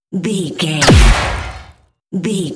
Dramatic hit electricity
Sound Effects
Atonal
heavy
intense
dark
aggressive